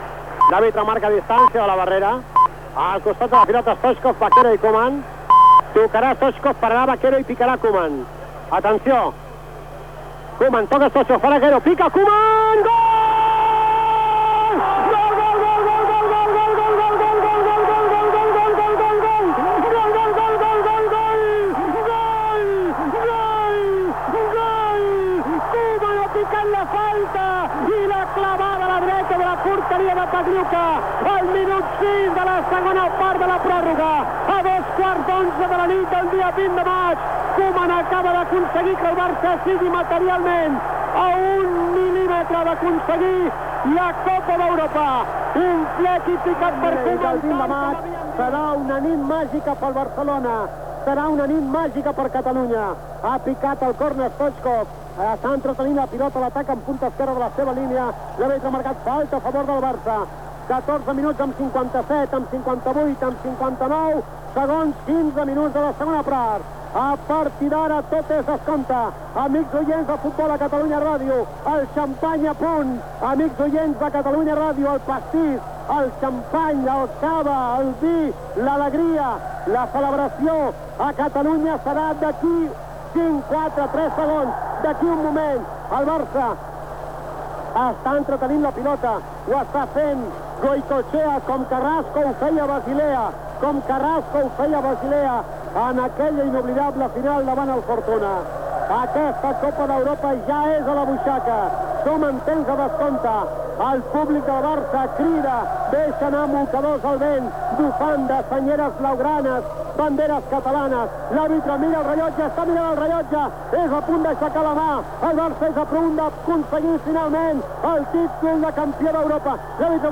Final de la Copa d'Europa de futbol 1991-92, disputada a l'Estadi de Wembley de Londres, entre la Sampdoria i el FC Barcelona. Gol de Ronald Koeman a la segona part de la pròrroga, últims instants del partit i lliurament de la copa d'Europa a Alexanko.
Esportiu